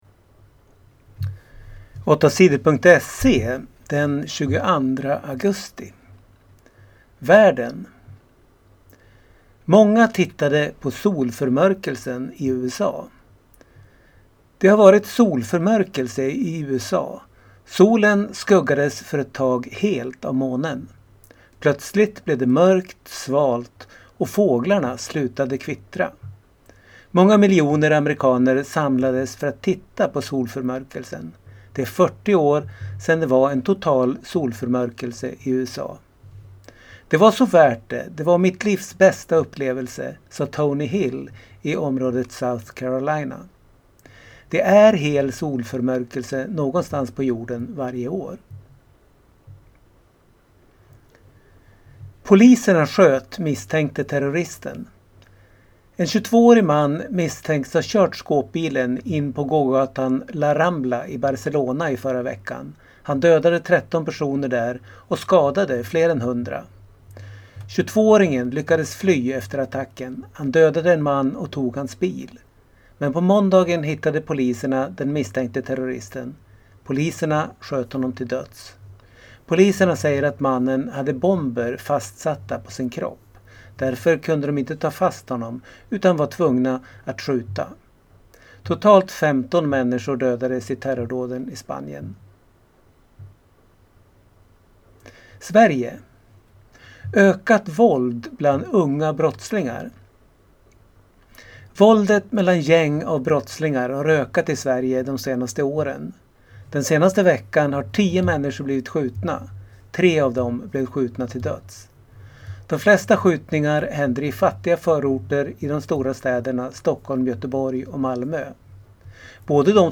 Lyssna på nyheter från tisdagen den 22 augusti
8 Sidor gör nyheter på lätt svenska.